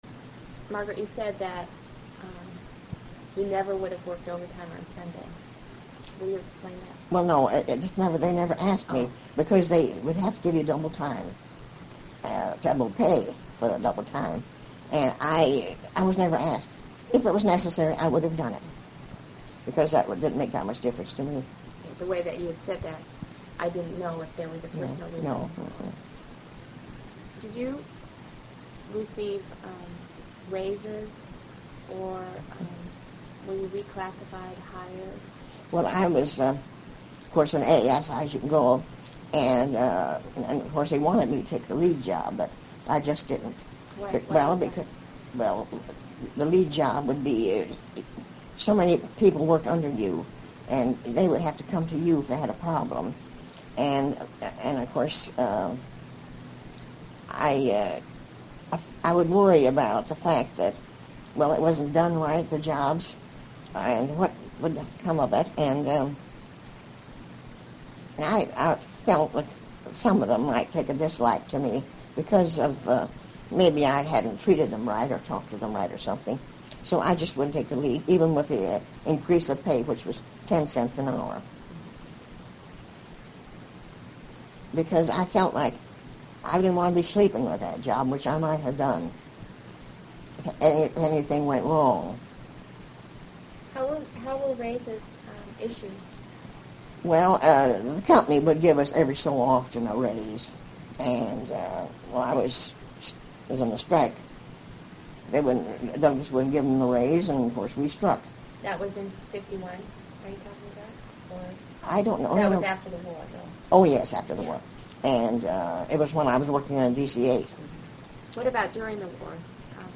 audio interview #2 of 3